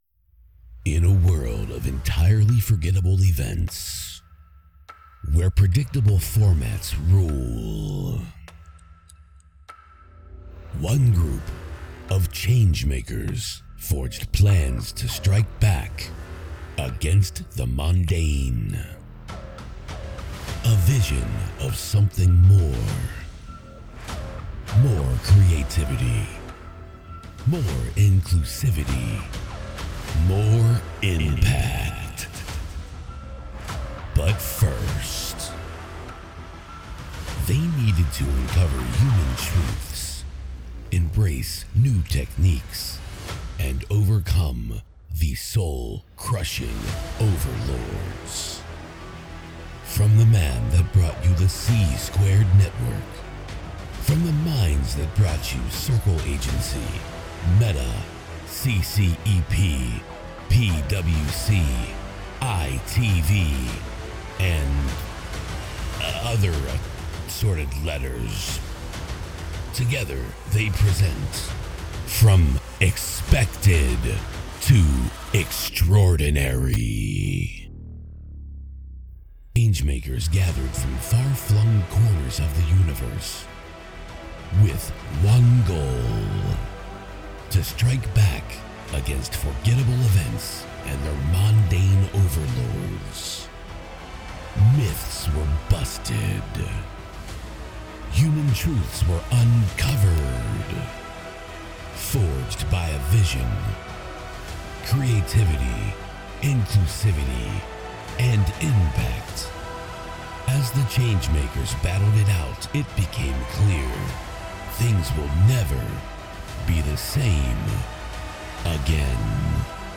"Movie Trailer" Voiceover for Corporate Event